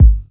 808-Kicks08.wav